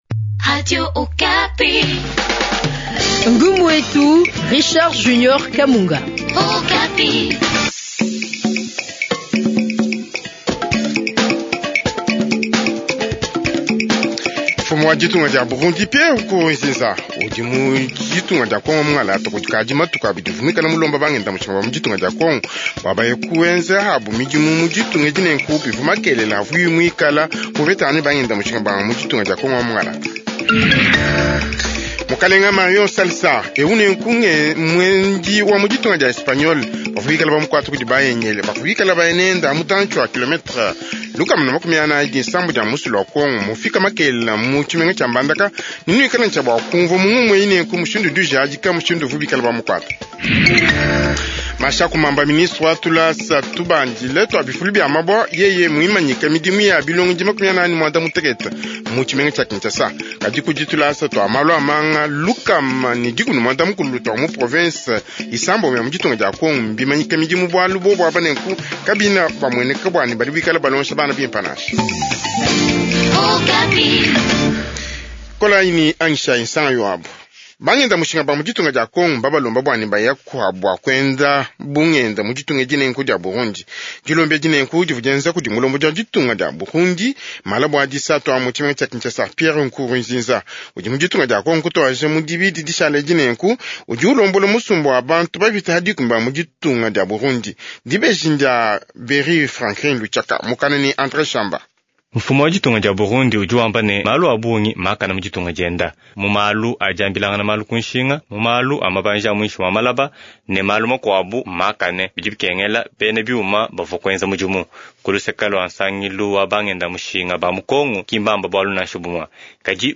Journal tshiluba du soir